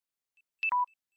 TW_Low_Battery.ogg